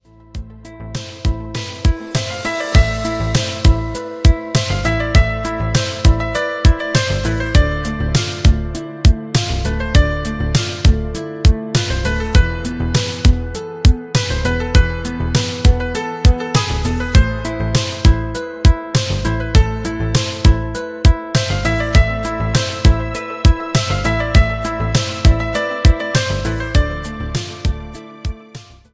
спокойные, без слов, пианино, красивая музыка